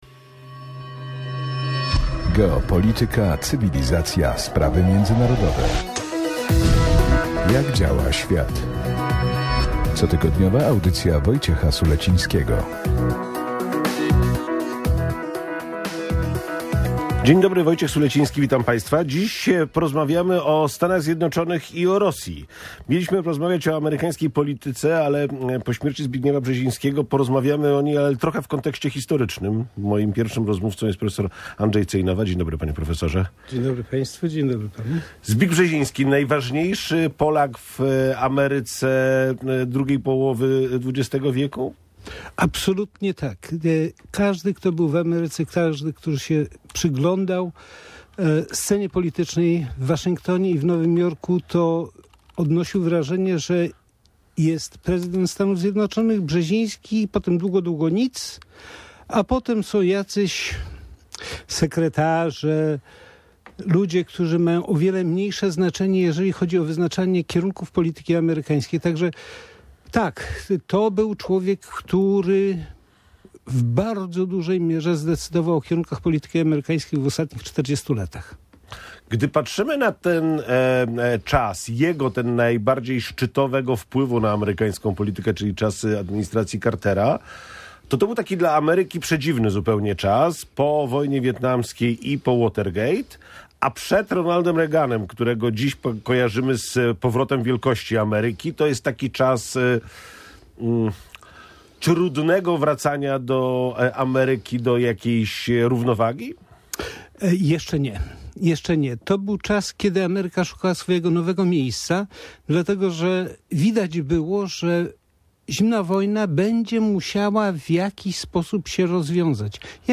telefonicznie